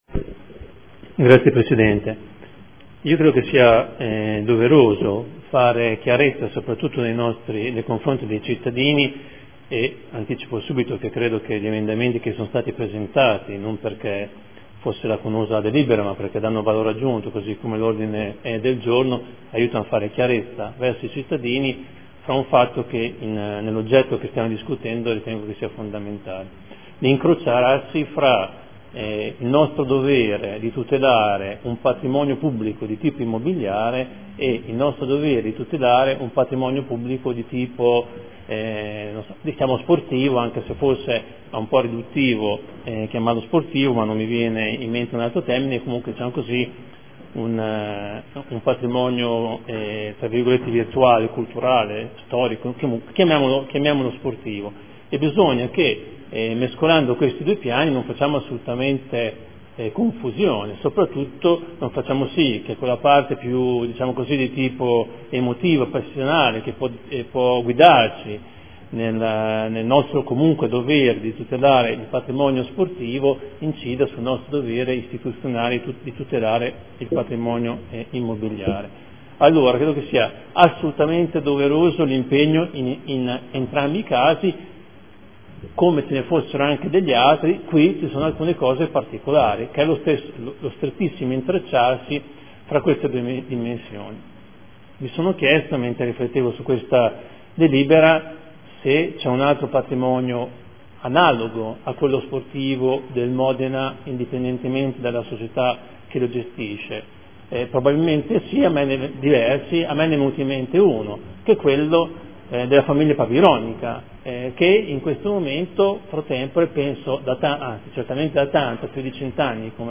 Convenzione per la gestione dello Stadio comunale Alberto Braglia al Modena FC S.p.a. – Modificazioni e prolungamento durata. Dibattito.